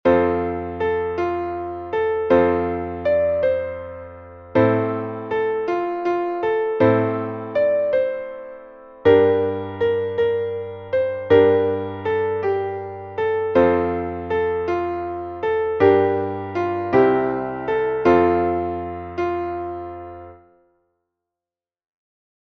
Traditionelles weihnachtliches Wiegenlied (14. Jahrhundert)